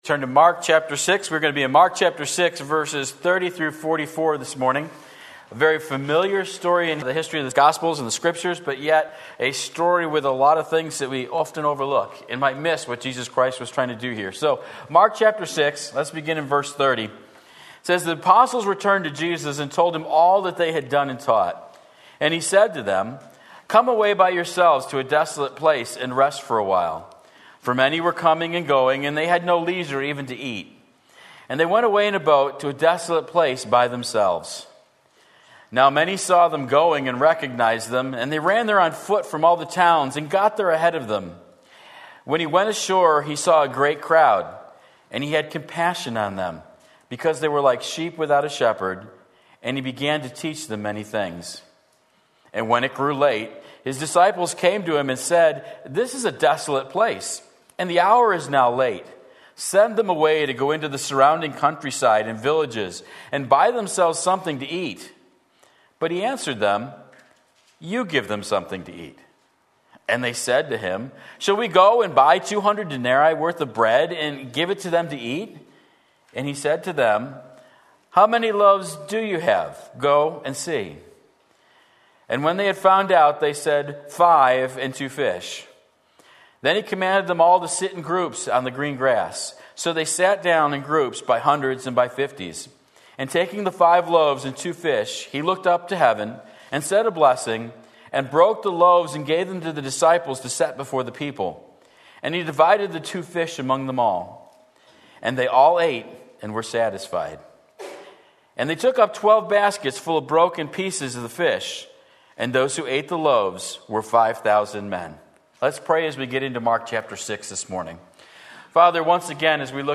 Sermon Link
More Than Enough Mark 6:30-44 Sunday Morning Service